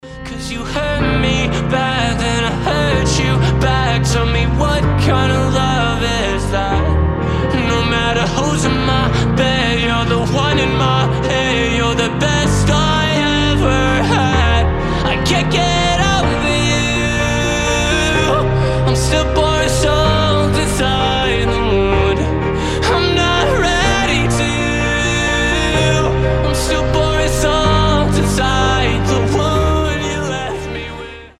Красивый женский голос EDM Future house
Slap house